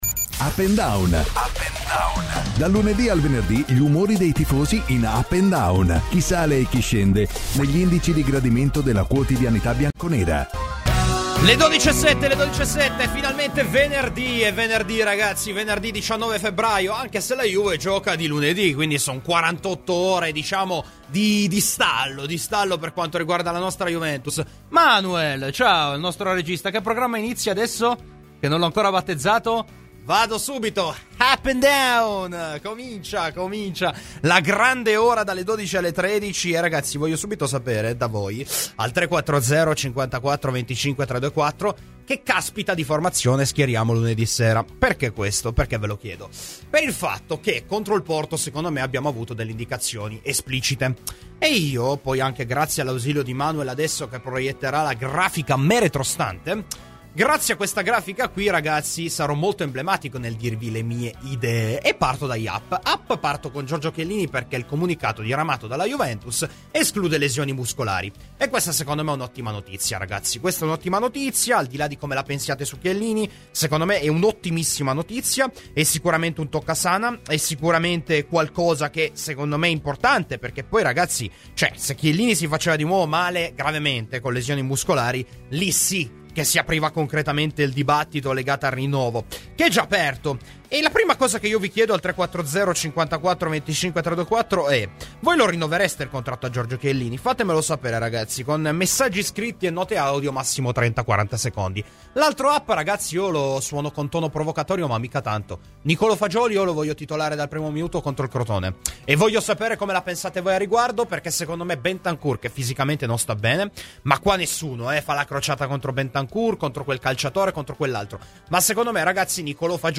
Intervistato da Radio Bianconera